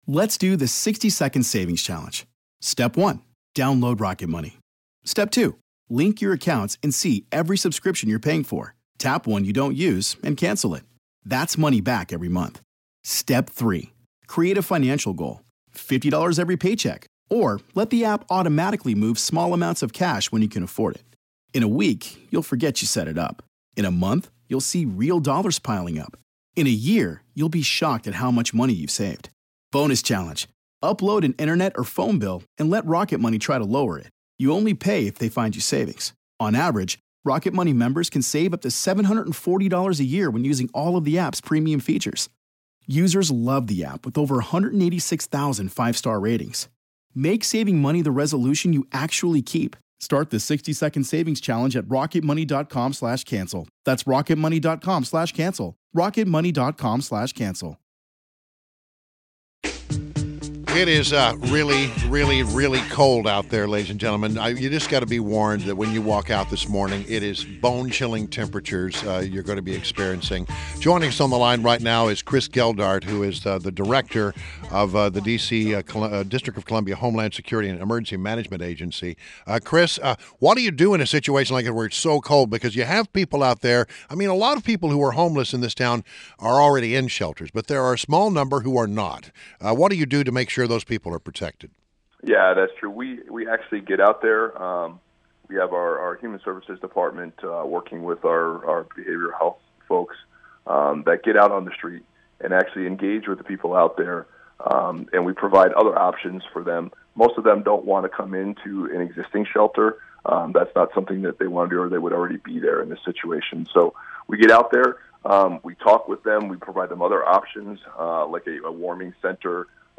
WMAL Interview - CHRIS GELDART - 12.16.16